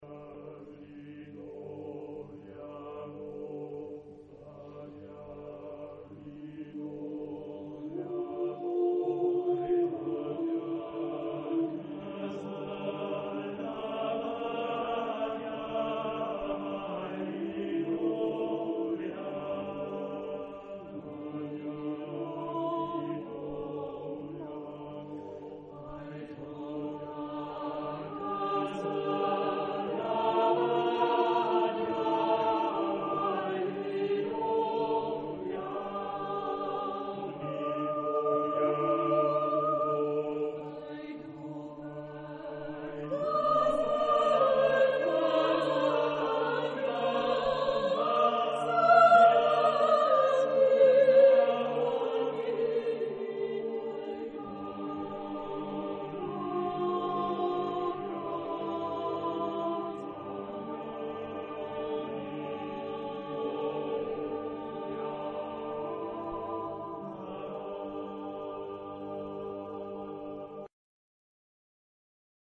Genre-Style-Forme : Chanson ; Folklore ; Profane
Type de choeur : SATB  (4 voix mixtes )
Tonalité : sol mineur (centré autour de)